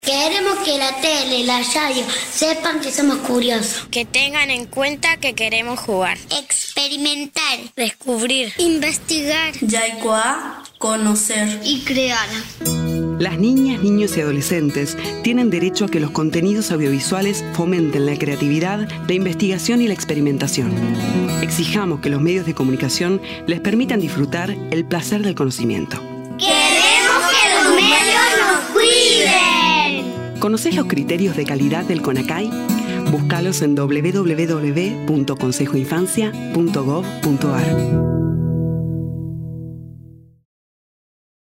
Spots radiales: Queremos que los medios nos cuiden
La producción contó con el trabajo creativo del equipo de coordinación del Consejo, la colaboración técnica de Radio SADOP y la participación de chicas y chicos de los talleres de la Villa 21-24 de CABA y de las provincias de Córdoba, Santiago del Estero y Tucumán.
Voces de niñas y niños: talleres del Centro Cultural de la Villa 21-24, de Radio Rimbombante de la Ciudad de Córdoba, y familias de Córdoba, Santiago del Estero, Tucumán y Ciudad de Buenos Aires
Grabación en estudio (Radio SADOP)